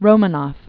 (rōmə-nôf, rō-mänəf, rə-)